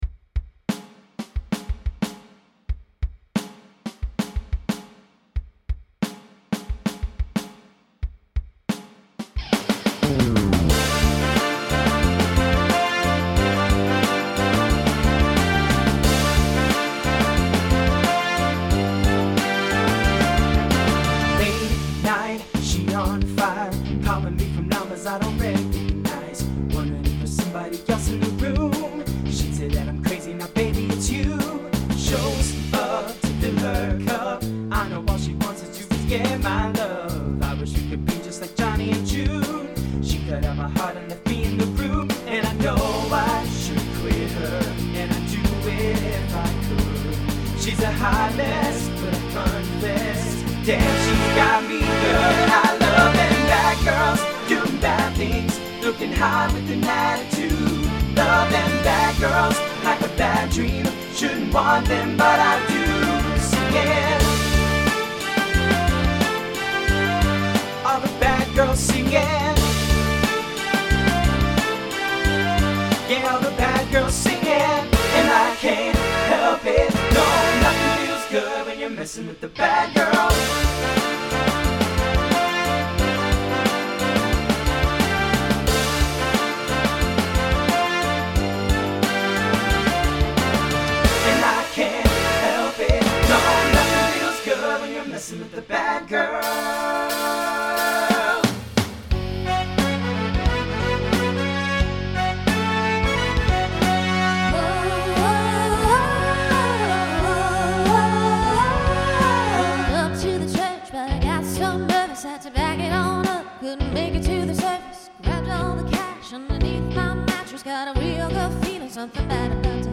TTB/SSA
Voicing Mixed Instrumental combo Genre Country , Pop/Dance